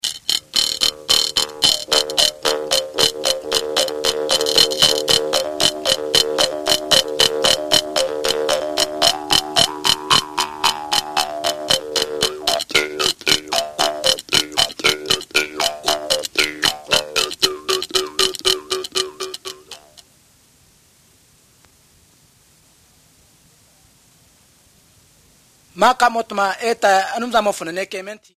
These are recorded by mother-tongue speakers